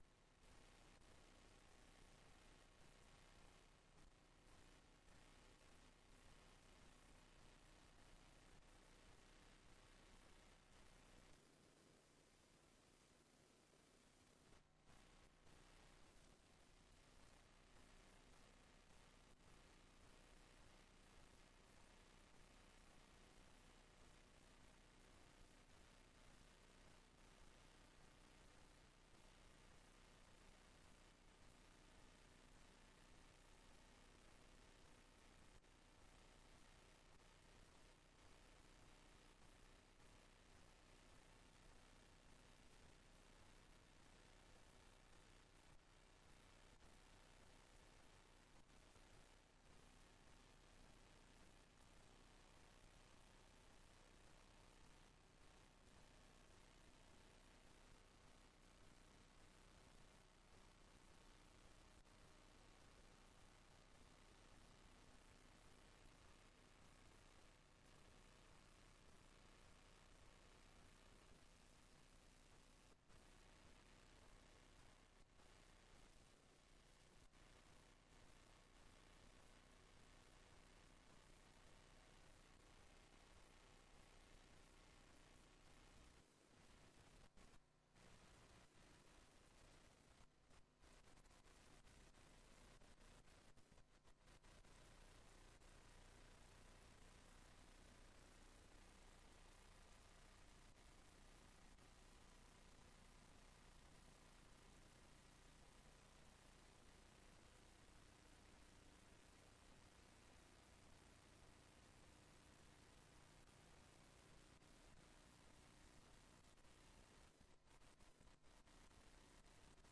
Raadsbijeenkomst 07 oktober 2025 20:30:00, Gemeente Tynaarlo